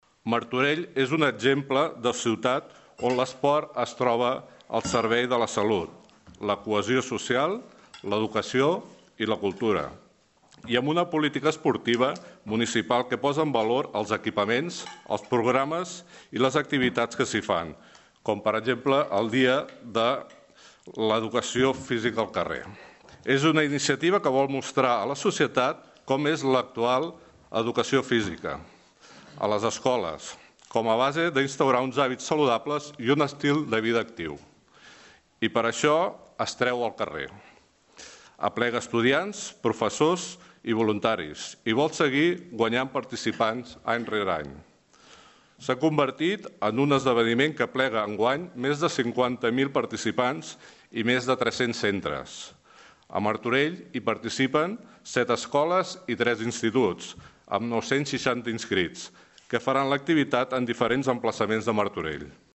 Aquest dimecres, s’ha dut a terme a la Sala de Plens de l’Ajuntament, la presentació institucional d’aquesta jornada esportiva, que se celebrarà el pròxim 26 d’abril.